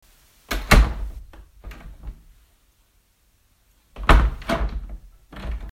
Download Door sound effect for free.
Door